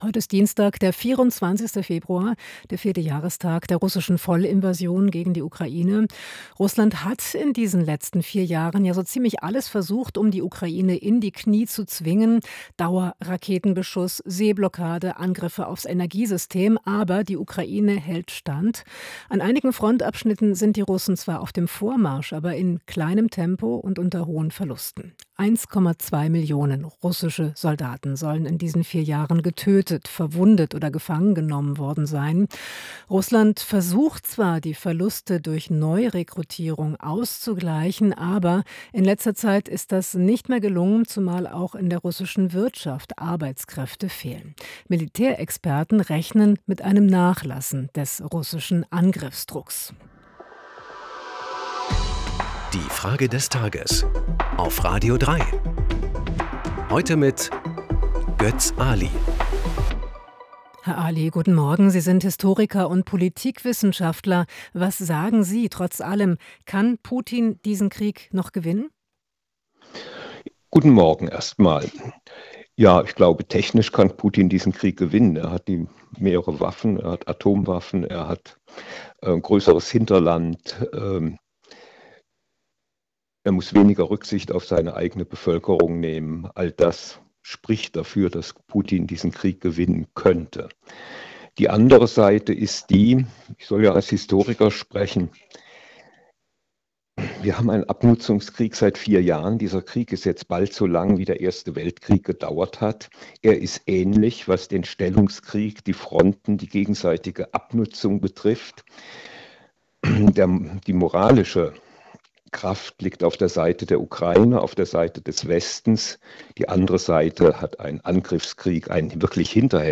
Historiker und Politikwissenschaftler Götz Aly.